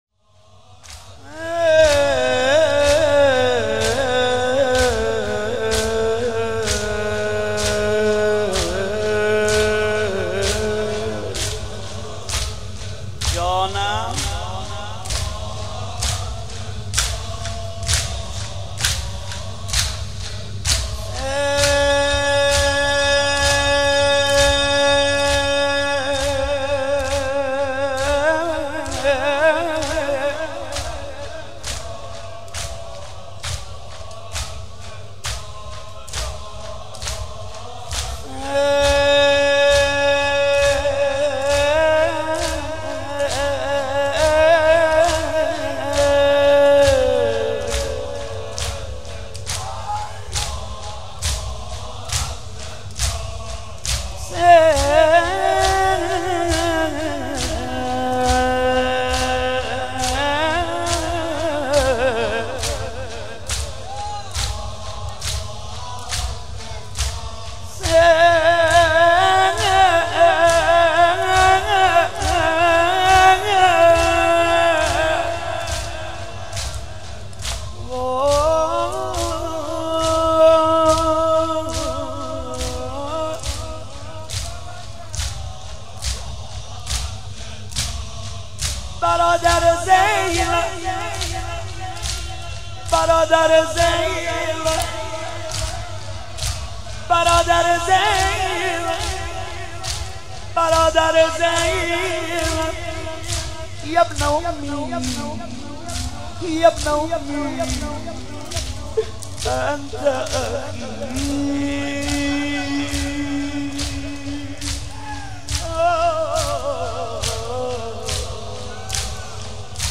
شب بیست و هشتم ماه رمضان؛ هیئت مکتب الزهرا(س)؛ مرداد 93